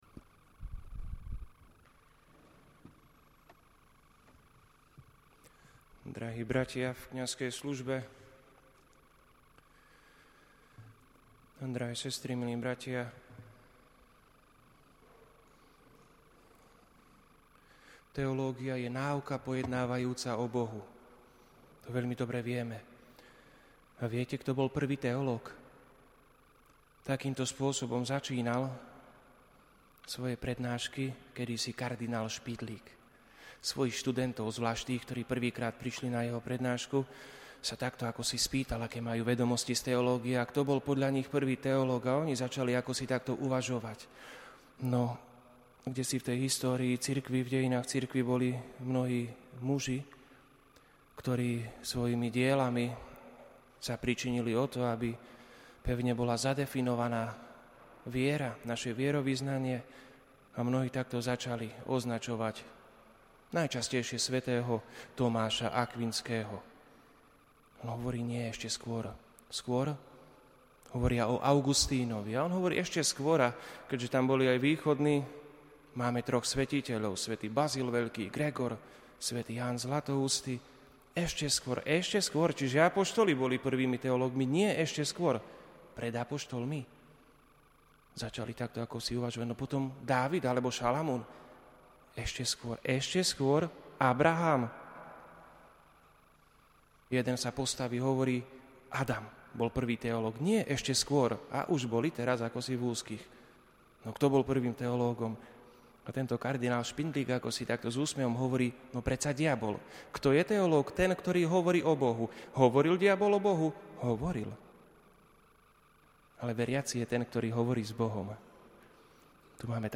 Ctitelia blahoslaveného Metoda Dominika Trčku sa stretli v sobotu 25. januára v bazilike pri jeho relikviách, aby sa spoločne modlili.